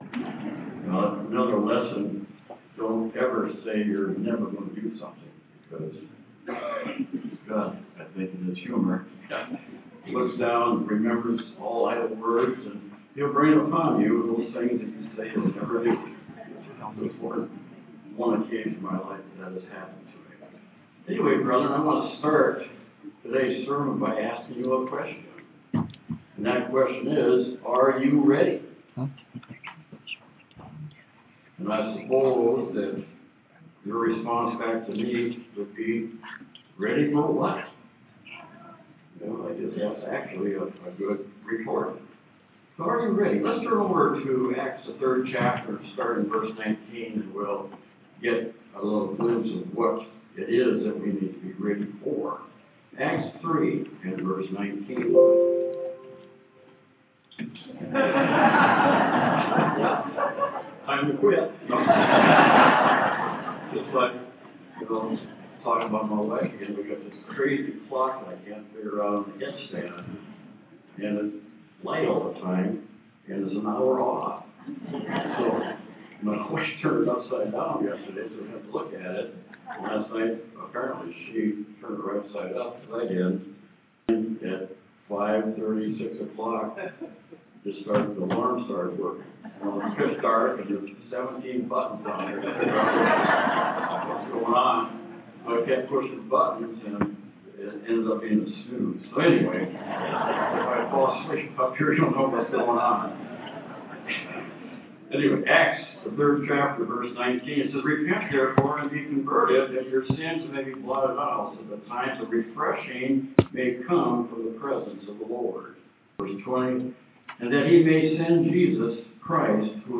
This sermon was given at the Galveston, Texas 2016 Feast site.